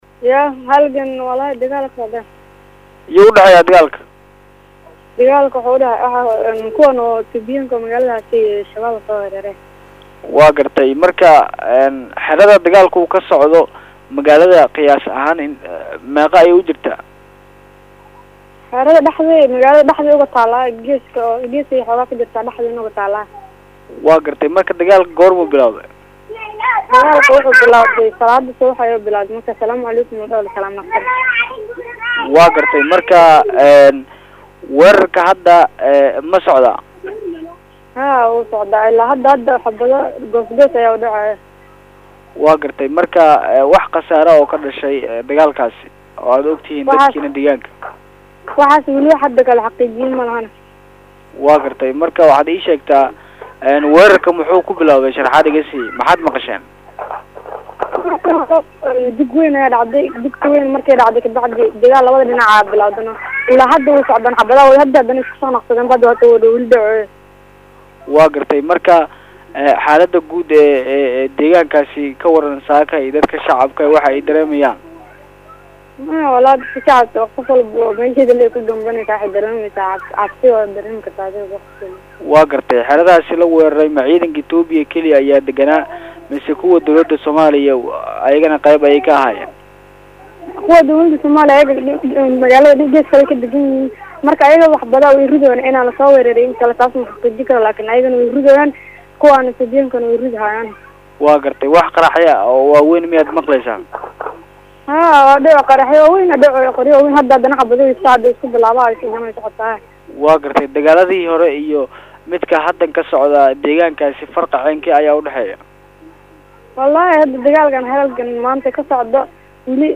Halkan ka dhageyso Qof Degaanka ah oo sharaxaya Weerarkaasi